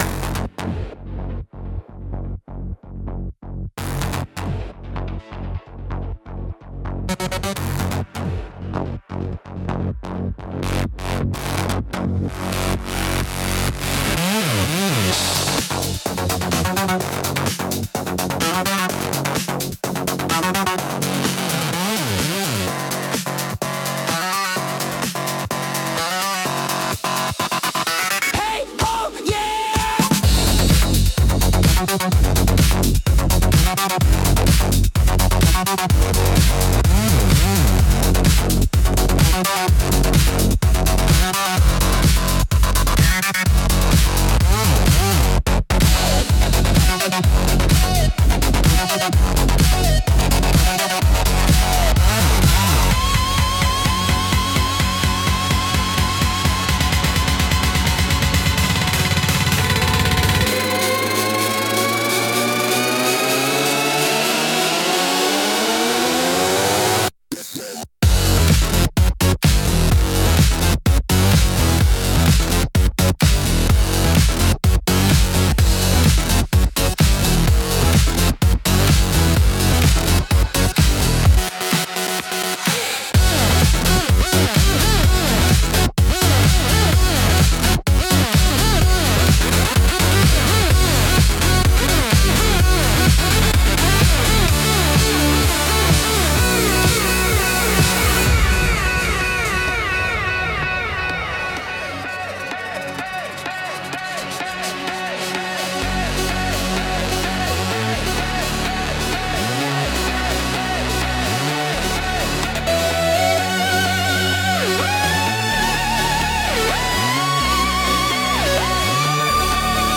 Instrumental - The Drop That Broke the System